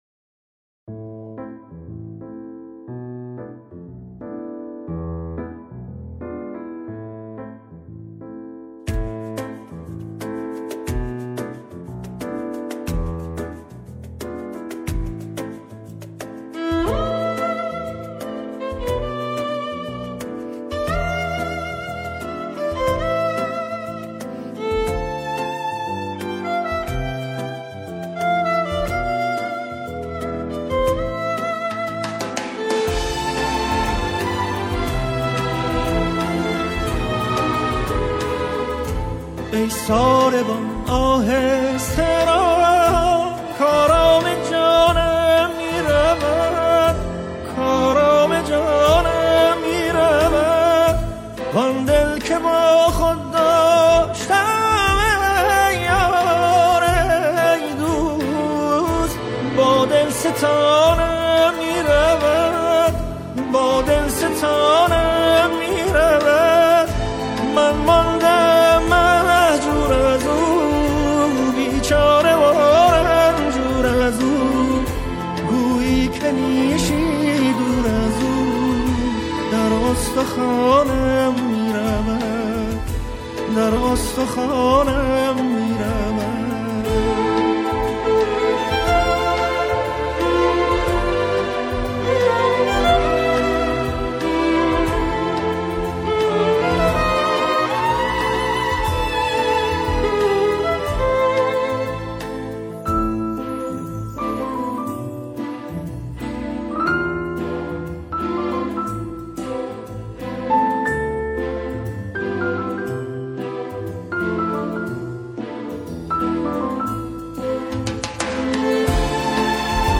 ویلنسل
پیانو